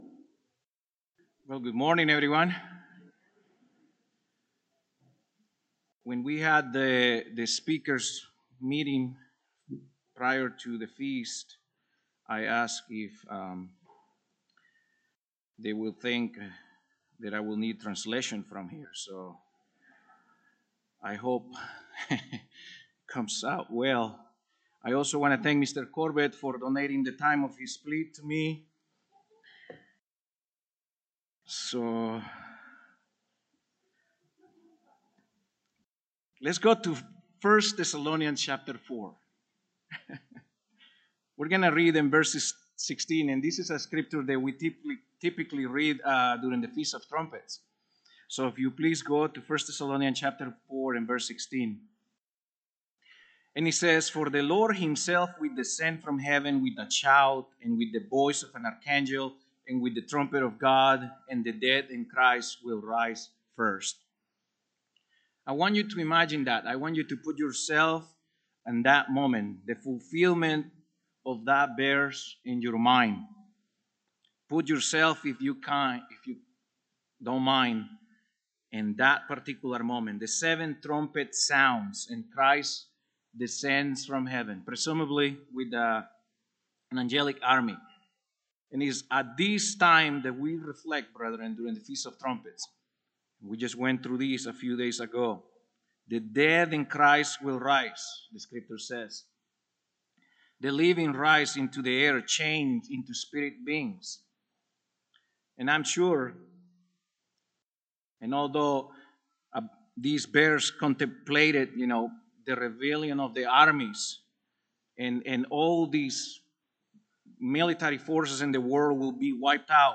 A split sermon during the Feast of Tabernacles in Glacier Country, Montana, 2020.
This sermon was given at the Glacier Country, Montana 2020 Feast site.